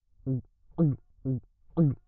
Gulping Drink Sound Effect Free Download
Gulping Drink